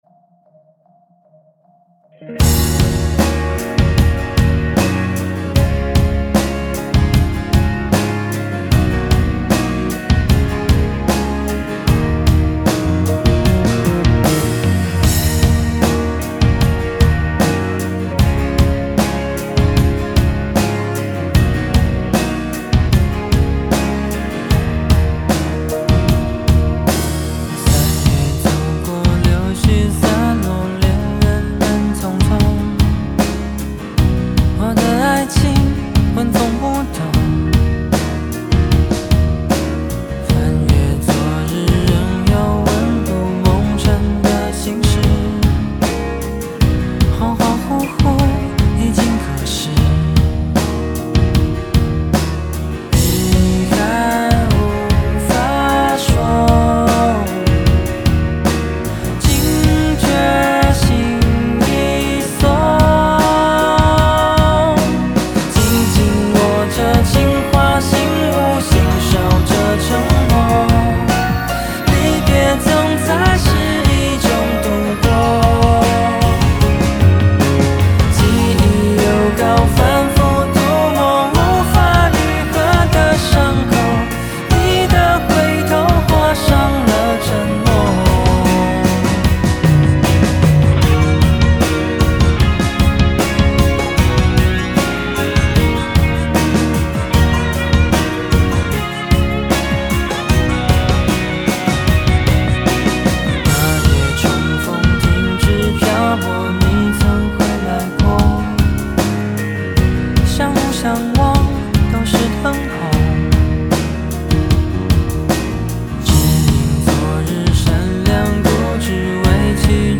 谱内音轨：架子鼓